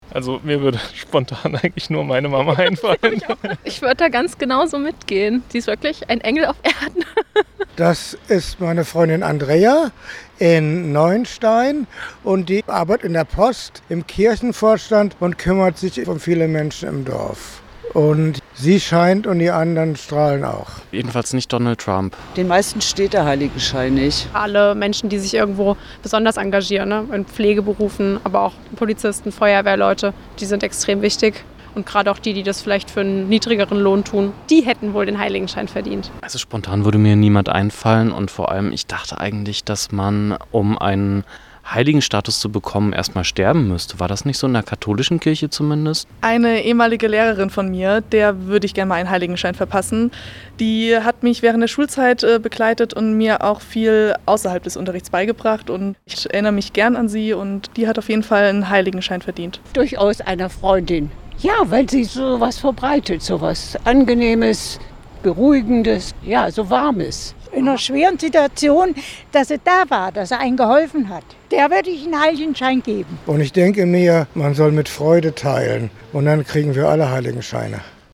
Hit from Heaven Umfrage zu Halo